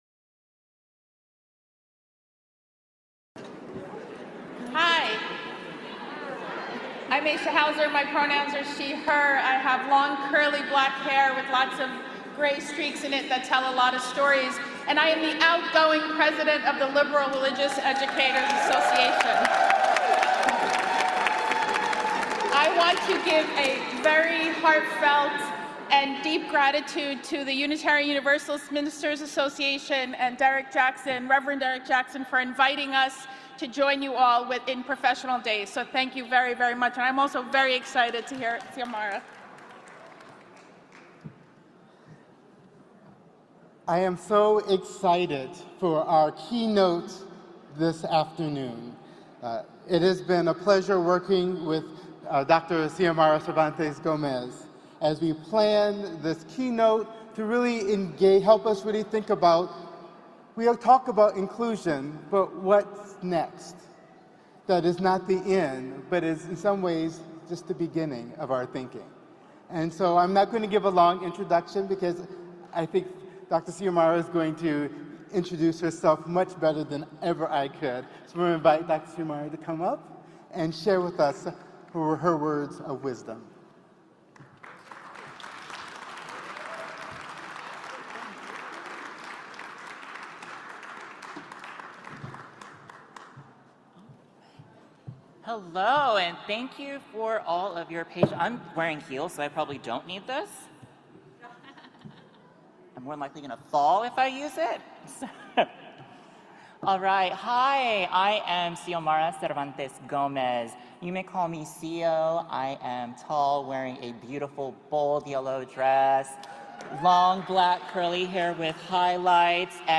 MD23-Keynote-1.mp3